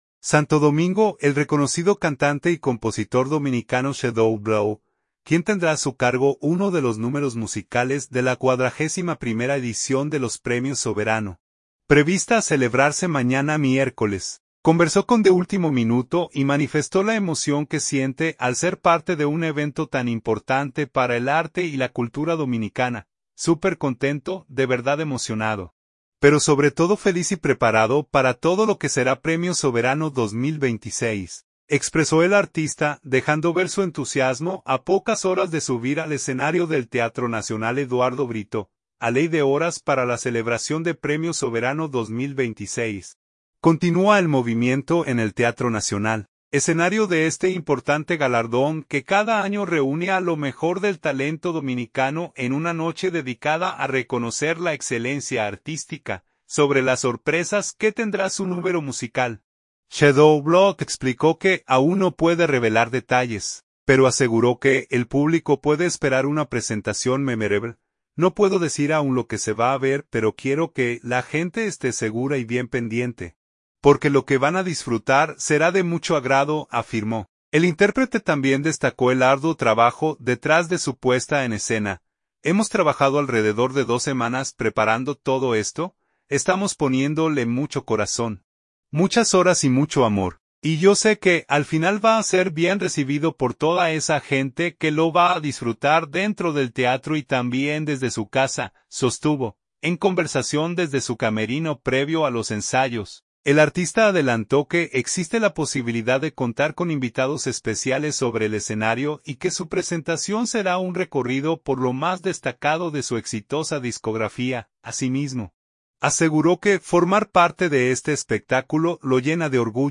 En conversación desde su camerino previo a los ensayos, el artista adelantó que existe la posibilidad de contar con invitados especiales sobre el escenario, y que su presentación será un recorrido por lo más destacado de su exitosa discografía.